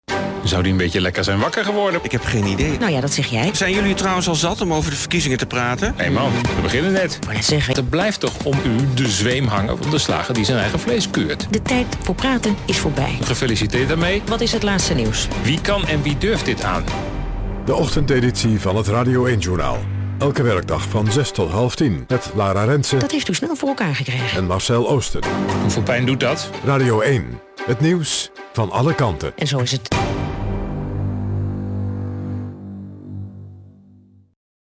hier de promo voor de ochtendeditie van het Radio 1 Journaal.